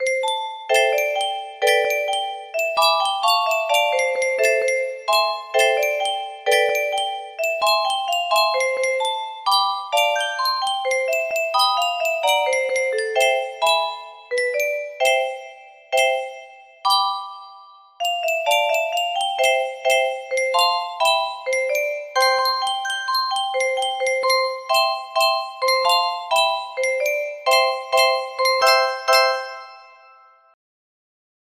Unknown Artist - Untitled music box melody
Grand Illusions 30 (F scale)
BPM 65